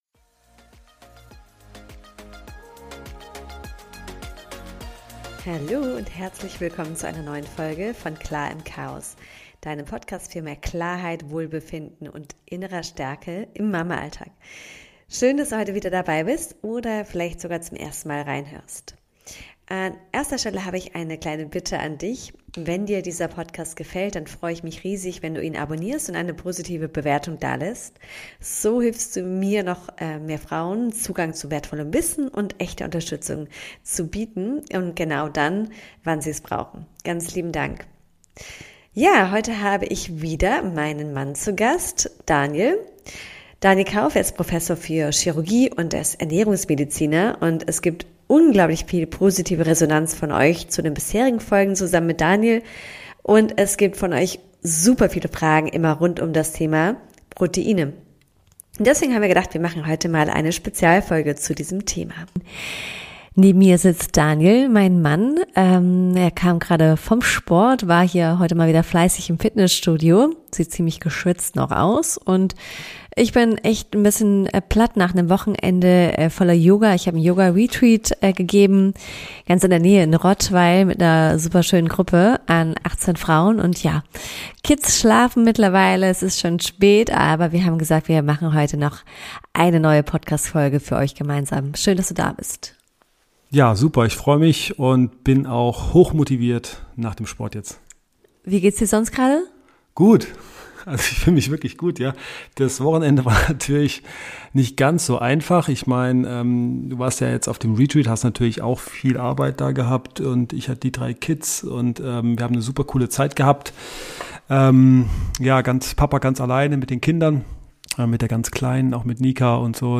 spricht mit ihrem Mann